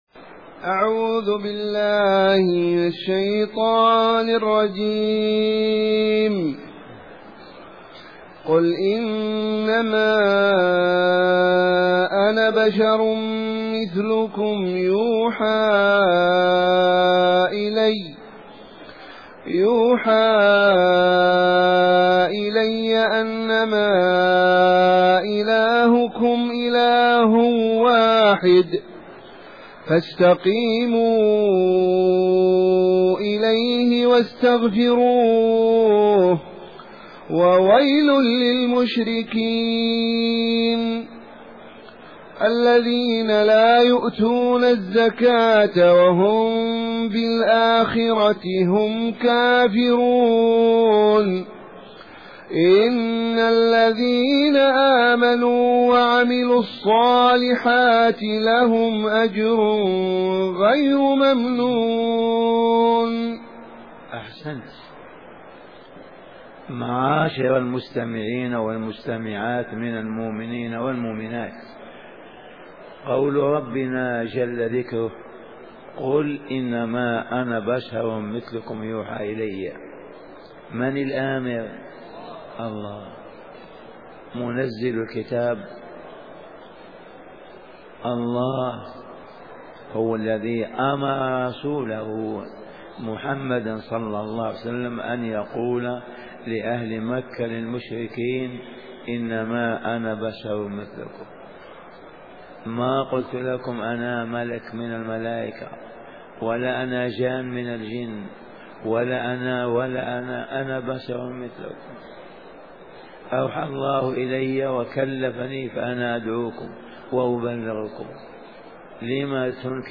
تفسير سورة فصلت كتاب أيسر التفاسير تأليف وشرح فضيلة الشيخ أبو بكر الجزائرى